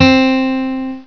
1 channel
snd_31825_Acoustic Guitar.wav